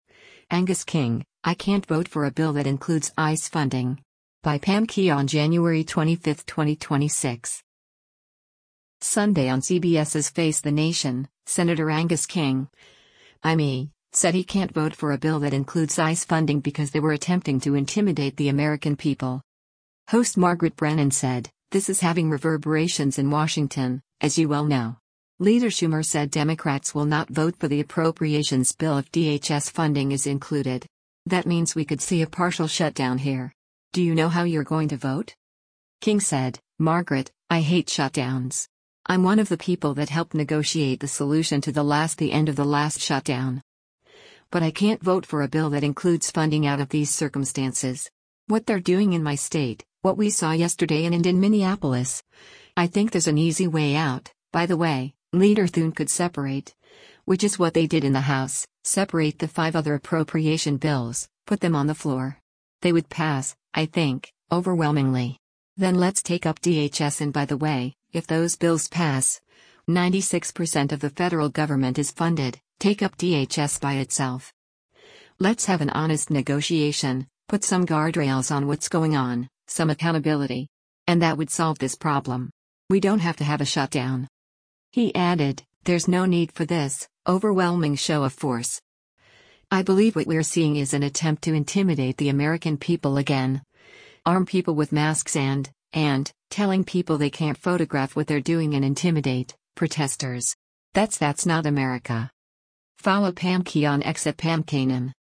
Sunday on CBS’s “Face the Nation,” Sen. Angus King (I-ME) said he can’t vote for a bill that includes ICE funding because they were attempting “to intimidate the American people.”